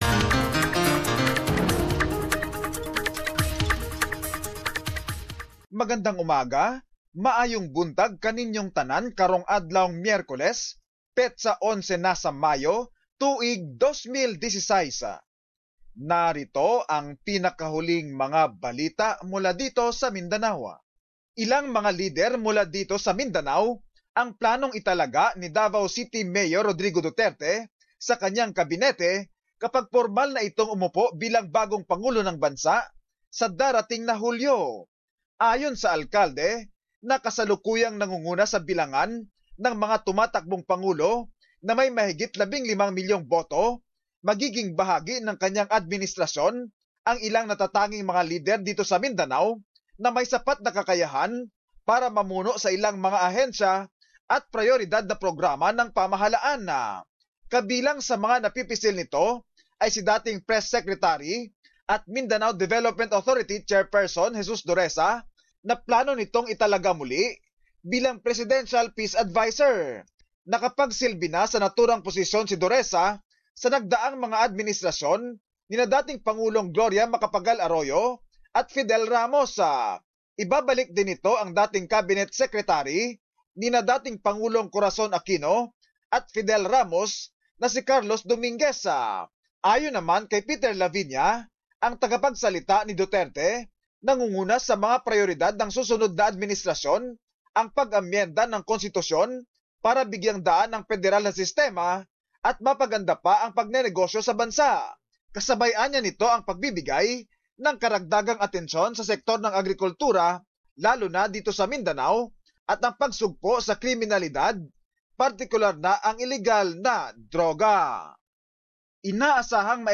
Mindanao News.